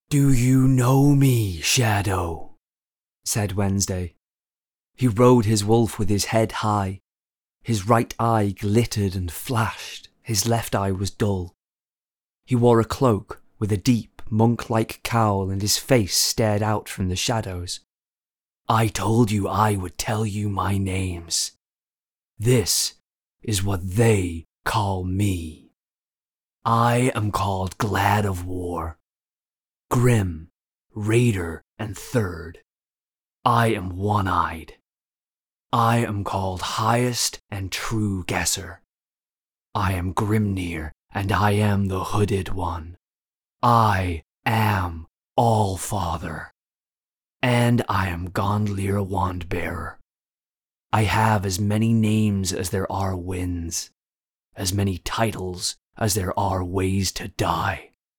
US Reel
His versatility shines through as he seamlessly transitions from embodying the relatable everyman to a downtrodden soldier to a sinister villain, delivering each role with care and authenticity.